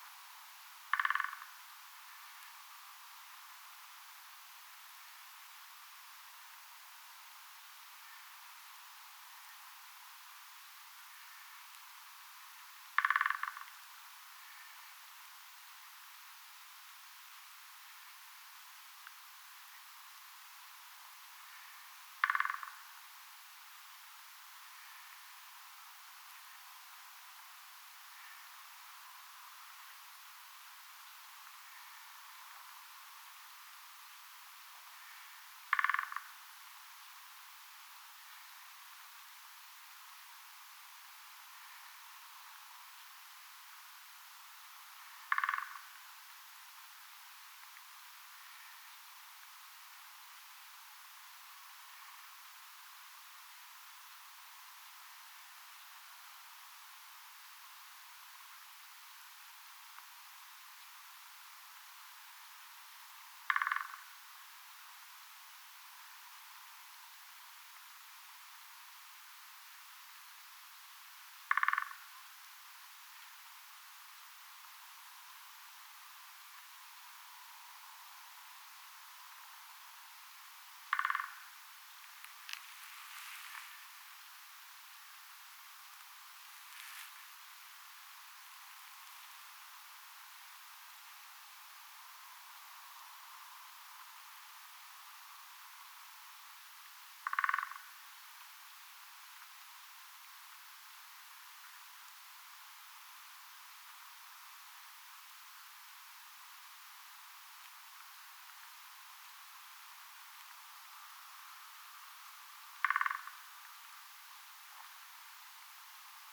käpytikan kevätrummustusta
kapytikan_kevatrummutusta.mp3